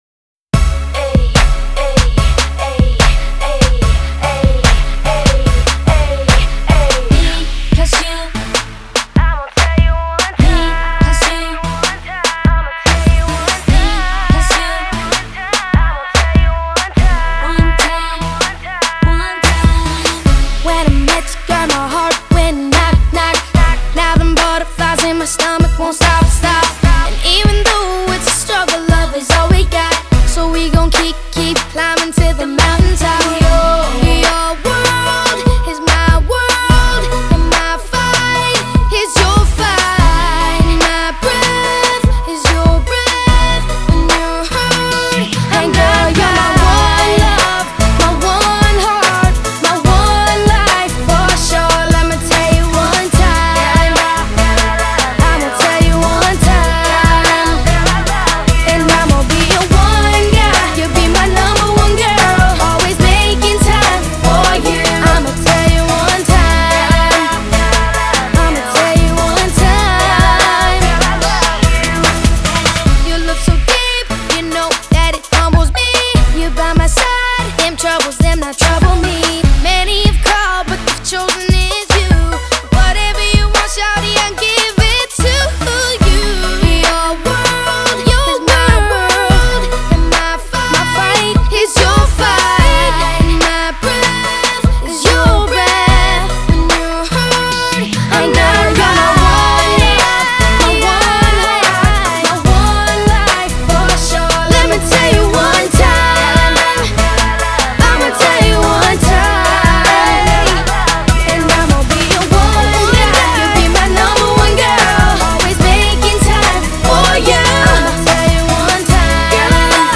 Категория: Попсовые песни